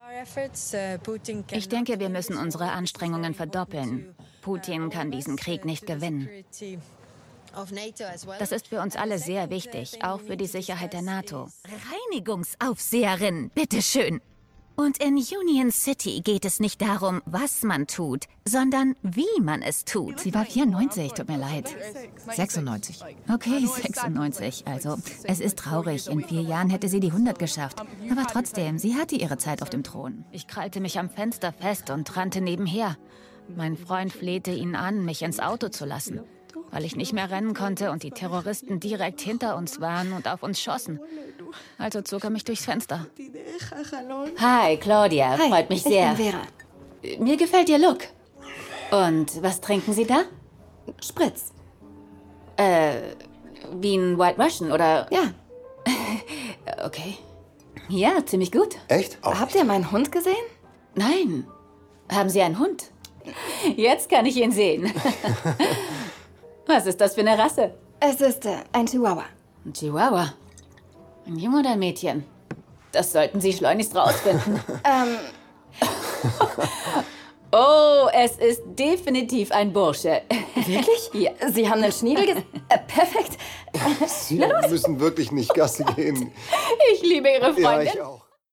Female
Approachable, Assured, Authoritative, Bright, Character, Confident, Conversational, Cool, Corporate, Energetic, Engaging, Friendly, Natural, Posh, Reassuring, Smooth, Soft, Upbeat, Versatile, Warm
Microphone: Austrian Audio OC18